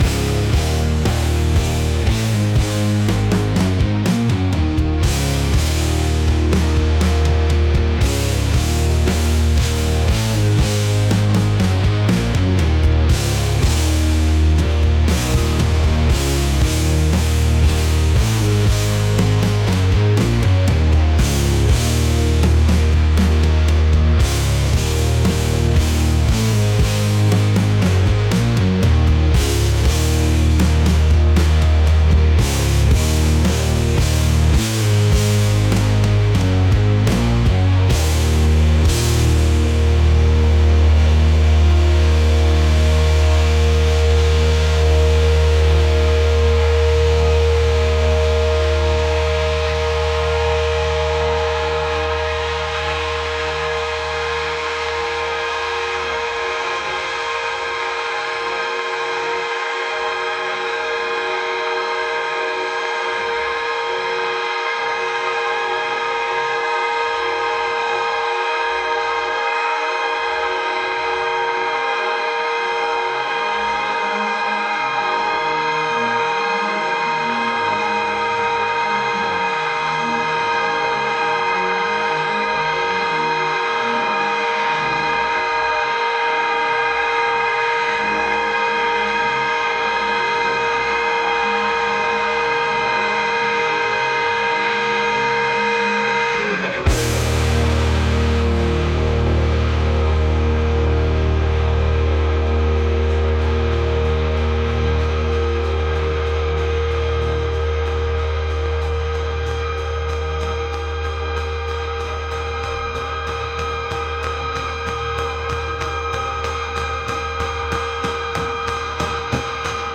rock | heavy